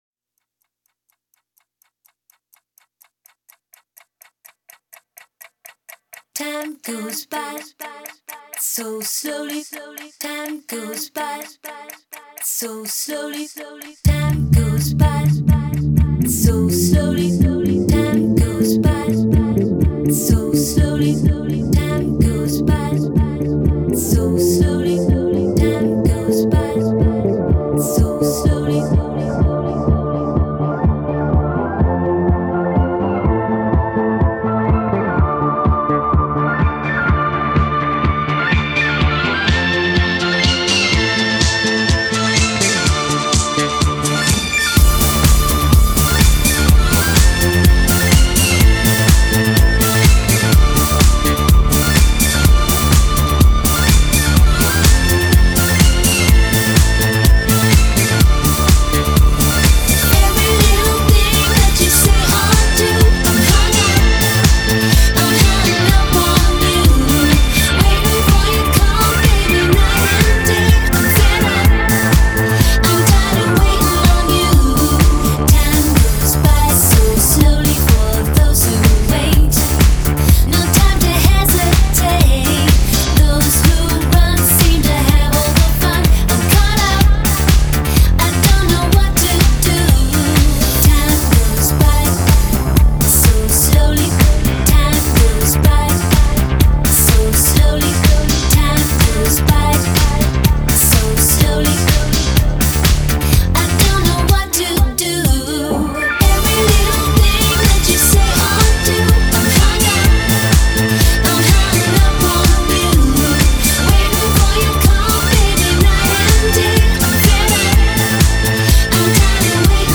انرژی کلاب دهه 2000 را منتقل می‌کند
Pop, Dance-Pop, Electronic